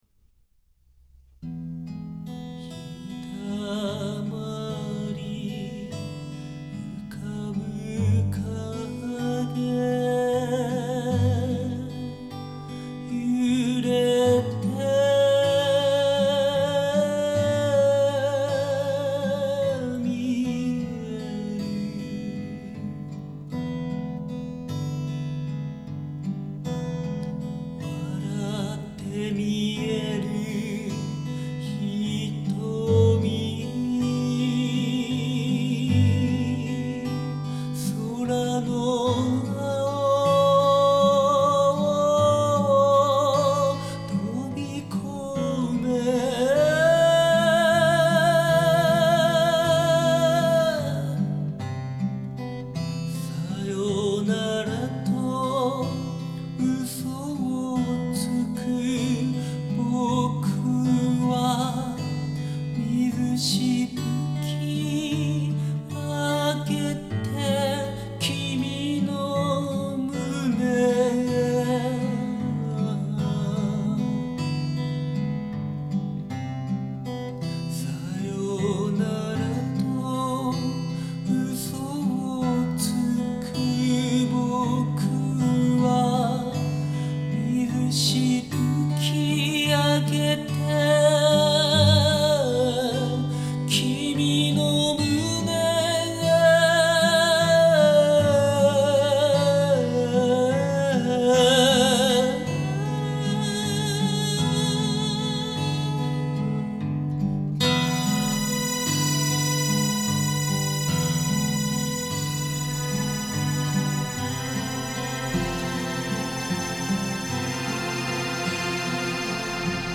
ギター
ピアノ
轟音と静寂の間を自在に行き交う骨太かつ繊細な歌を奏でる。